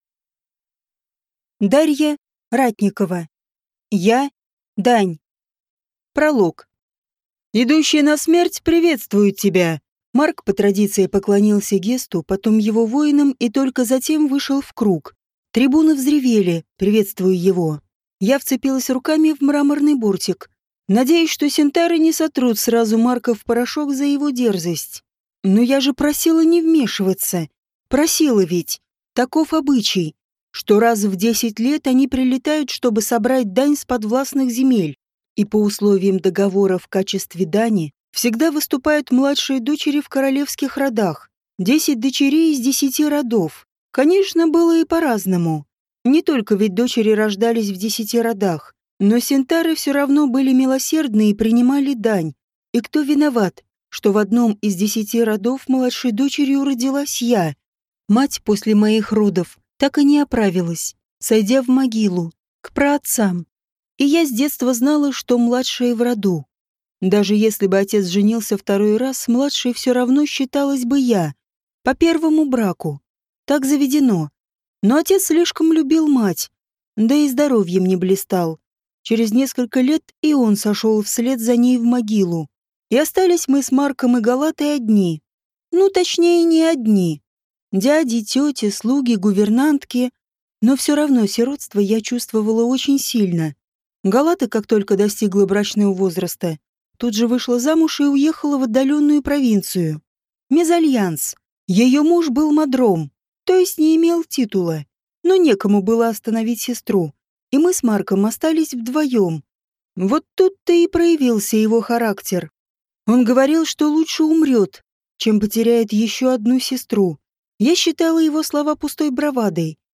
Аудиокнига Я – дань | Библиотека аудиокниг